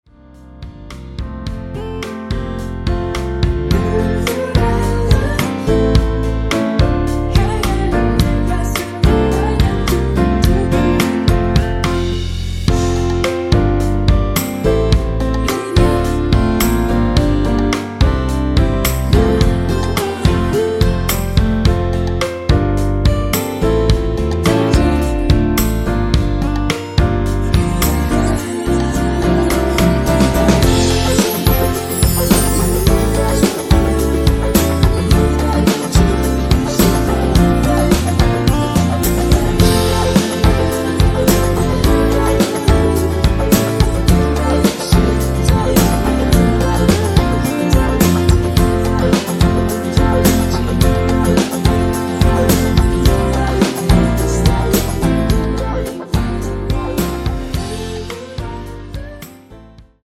원키에서(-2)내린 코러스 포함된 MR입니다.(미리듣기 확인)
앞부분30초, 뒷부분30초씩 편집해서 올려 드리고 있습니다.